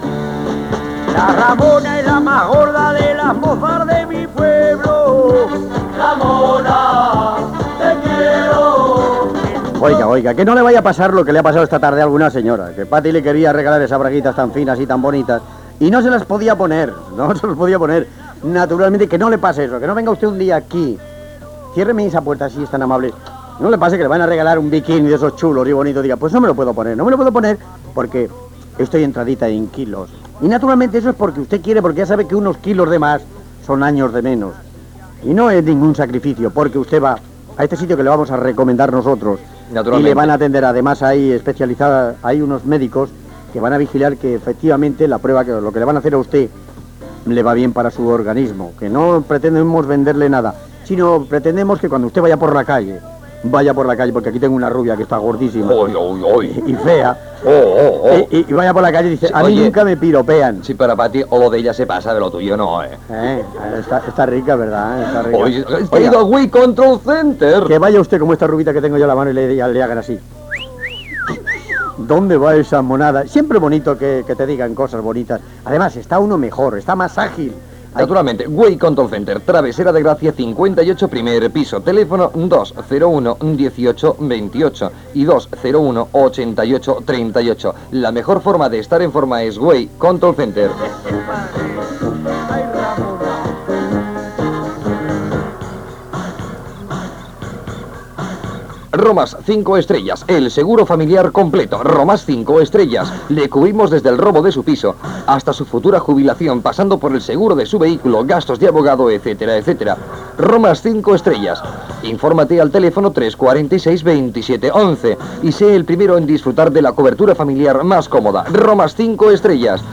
Publicitat i tema musical.
FM